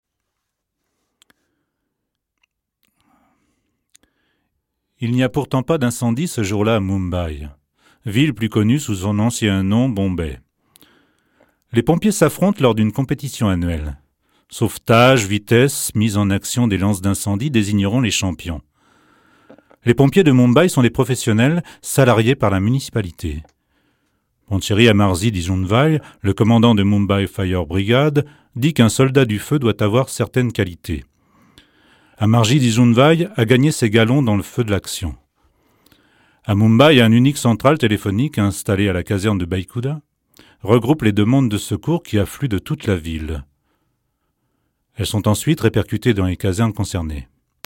voix off, reportage